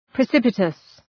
{prı’sıpətəs}